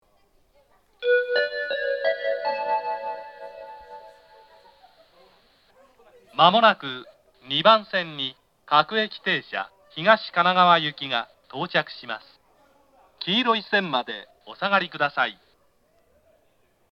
東海道型(男性)
接近放送